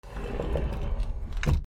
扉
/ K｜フォーリー(開閉) / K05 ｜ドア(扉)
キャスター付き